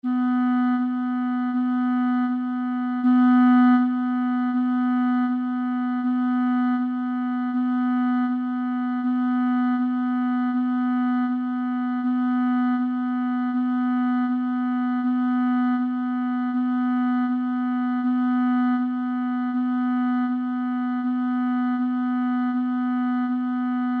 NOTAS MUSICAIS
NOTA SI